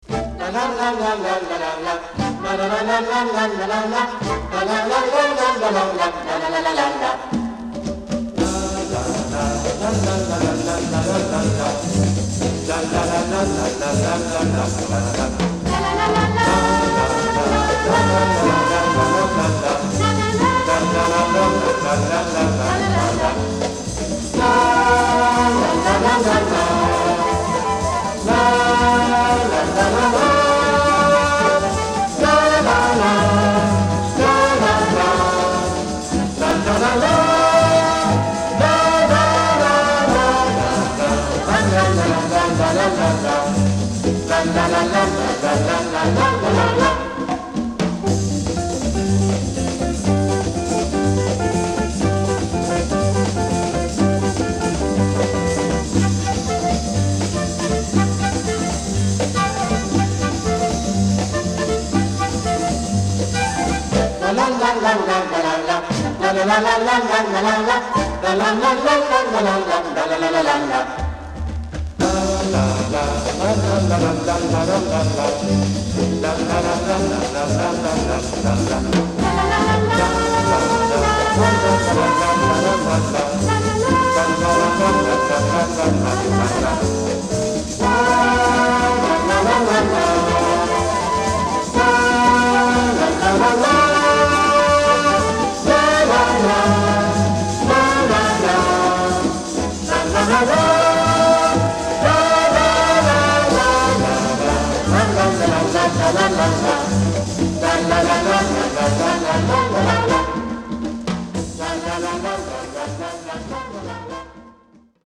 Jazz Vocal canada